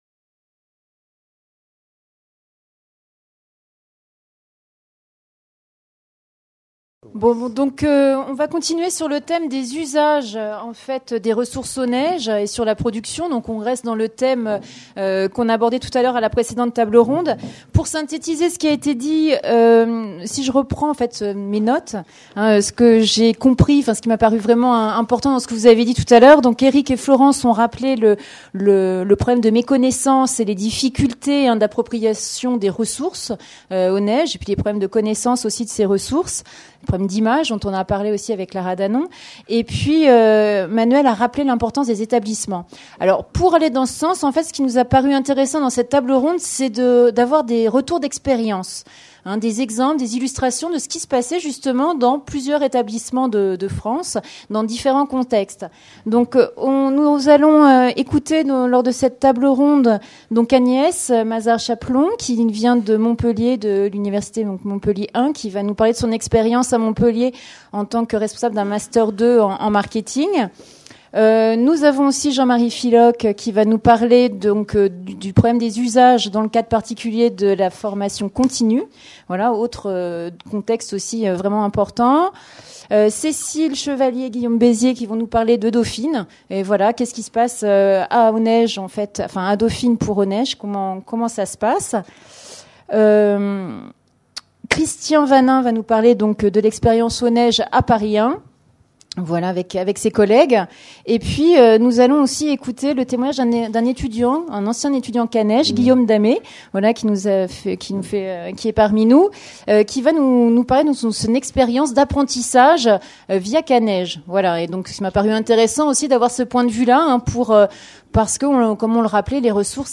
Table ronde 2 : Les usages des ressources Aunege et leur production | Canal U